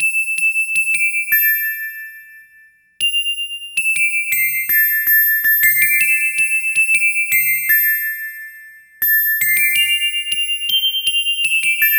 Bells 02.wav